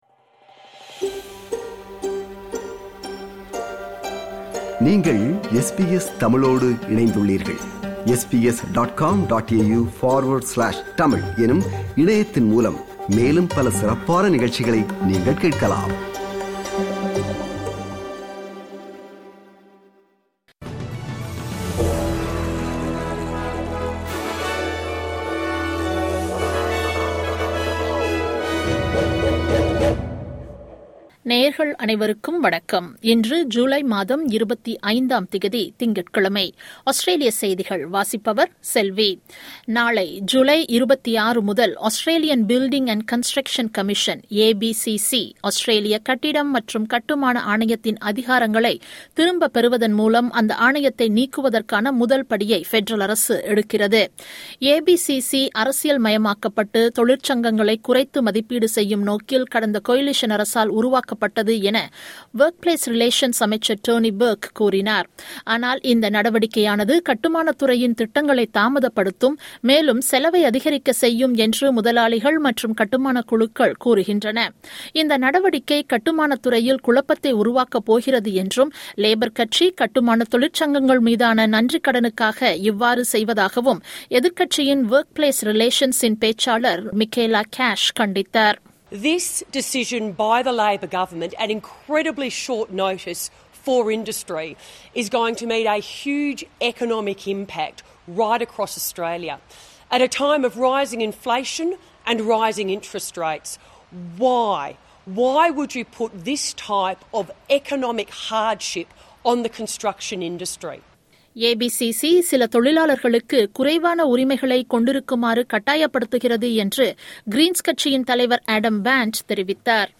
Australian news bulletin for Monday 25 July 2022.